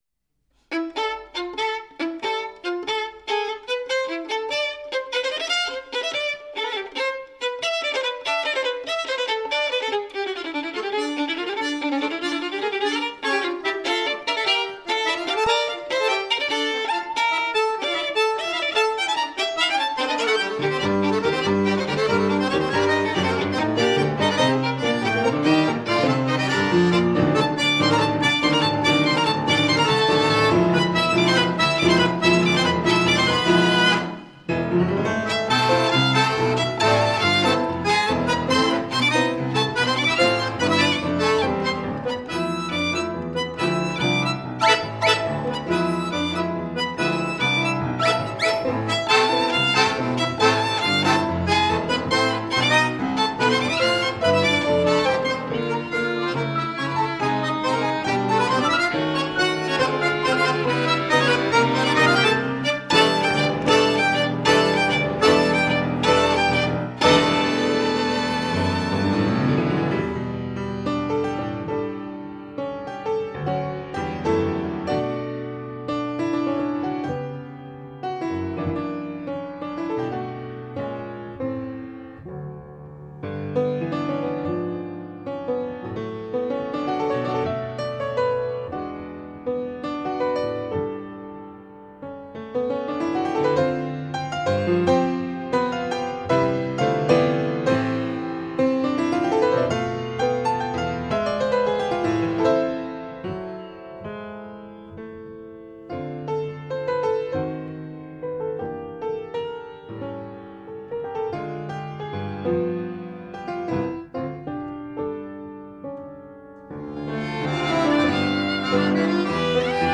Violino, Fisarmonica e Pianoforte